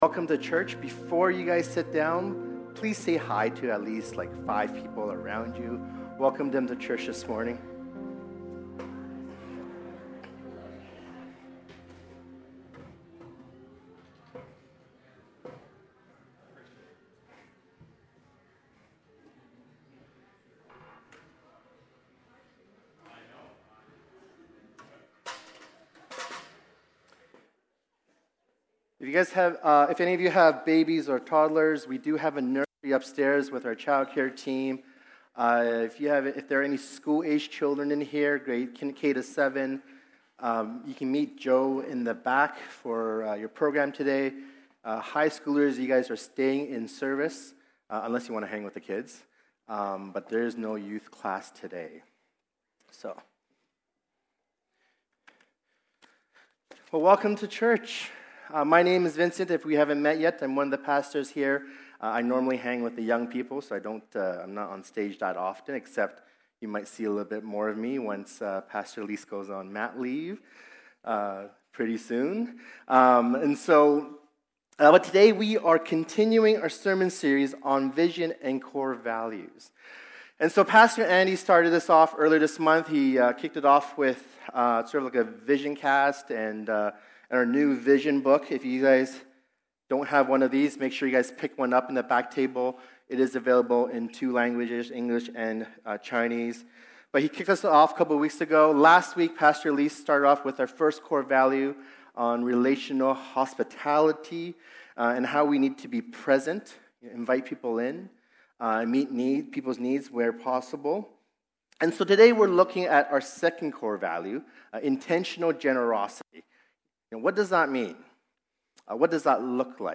Sermons | Home Church